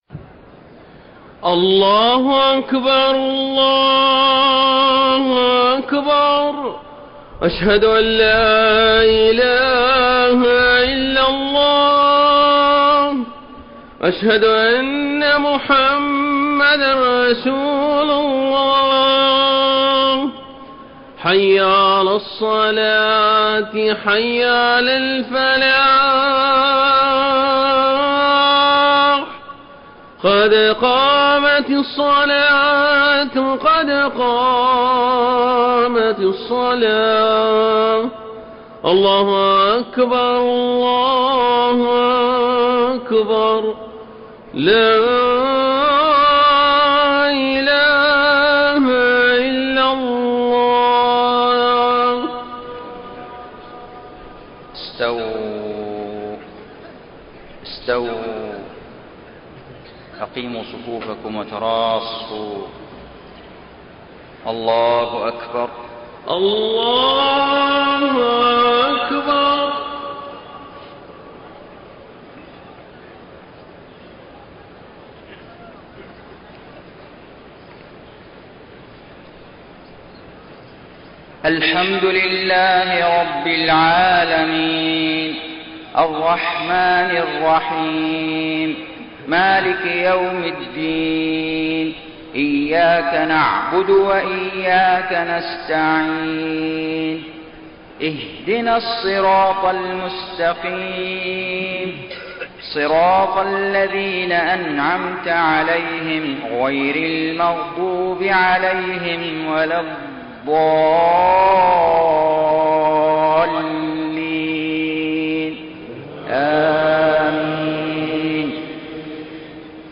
صلاة الفجر 24 ذو الحجة 1432هـ من سورة الفرقان 21-42 > 1432 🕋 > الفروض - تلاوات الحرمين